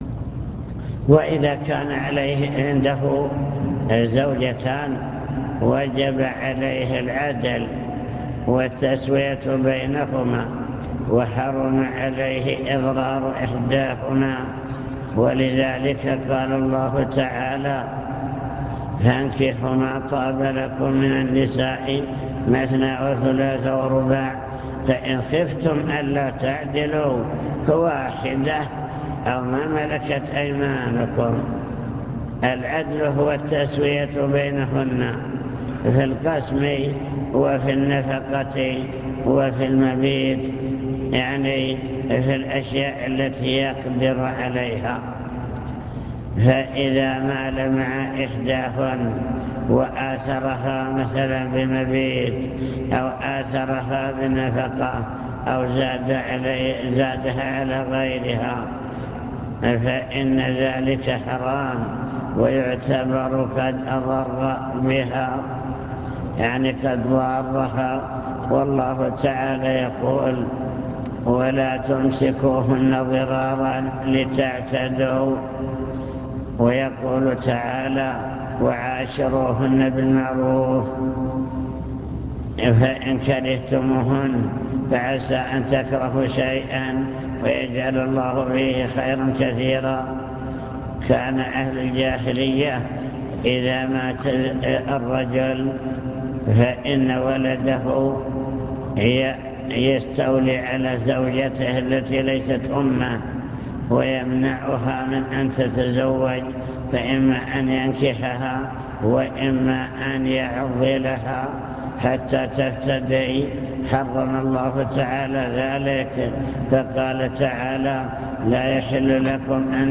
المكتبة الصوتية  تسجيلات - محاضرات ودروس  محاضرة واجب المسلم نحو أسرته واجب الرجل نحو أسرته في الأمور الدنيوية